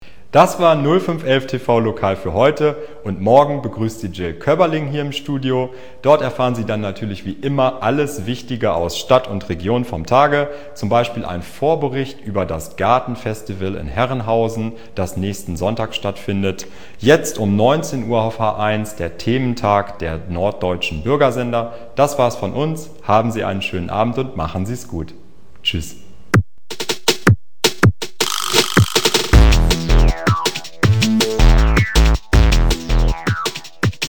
deutscher Sprecher
Kein Dialekt
Sprechprobe: Industrie (Muttersprache):
german voice over artist